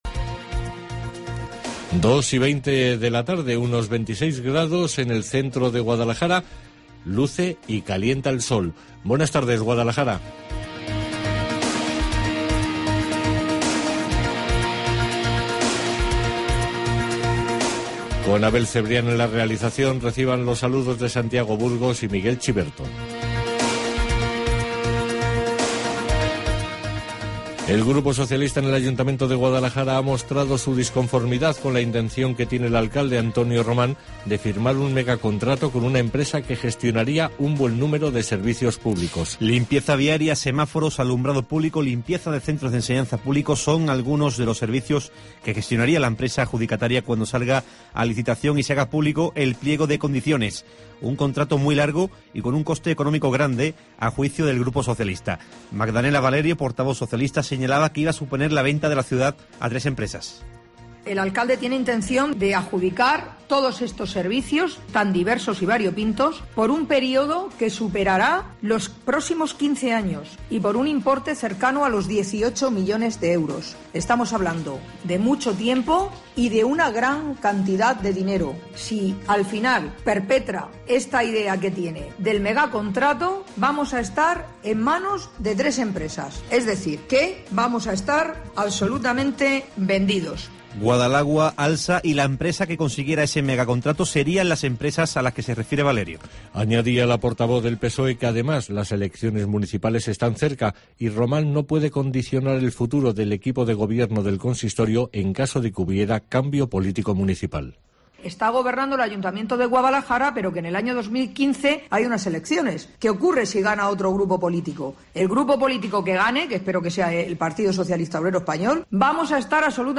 Informativo Guadalajara 13 DE MAYO